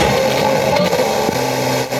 120BPMRAD0-R.wav